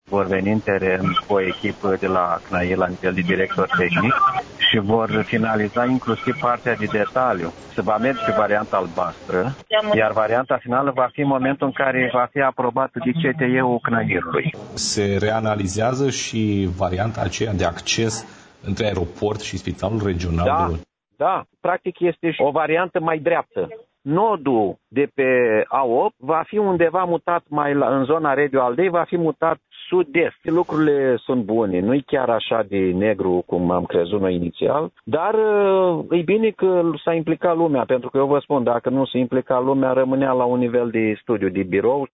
Într-o intervenție pentru postul nostru de radio